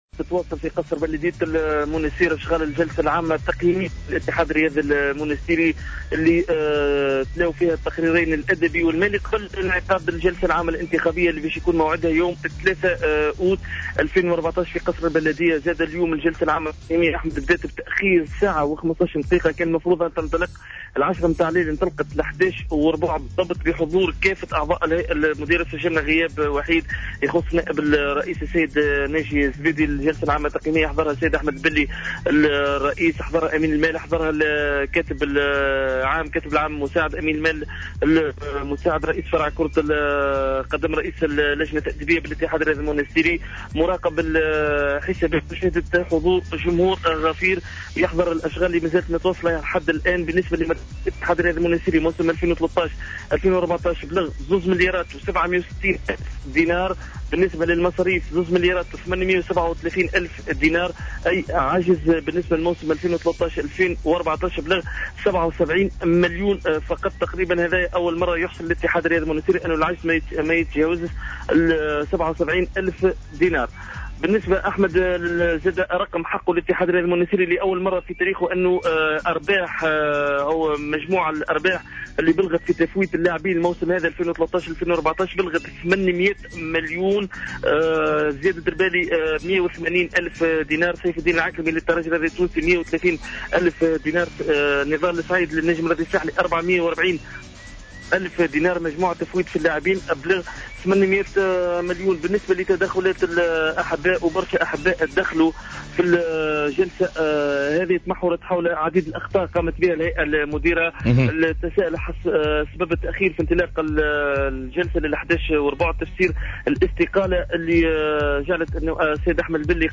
أكثر تفاصيل حول أشغال الجلسة العامة في المراسلة التالية